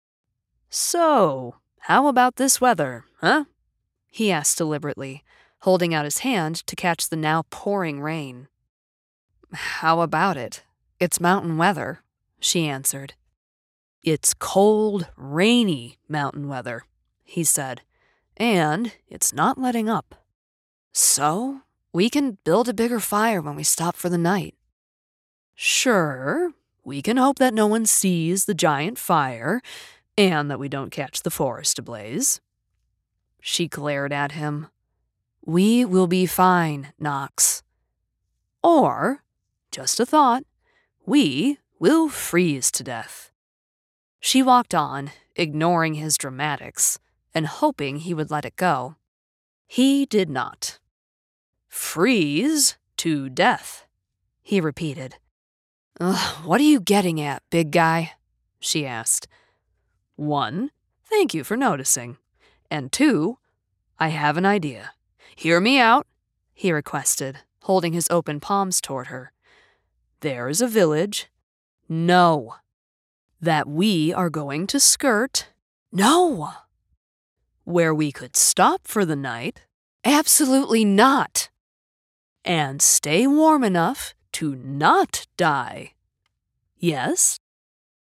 southern us | natural
standard british | character
standard us | natural
audiobook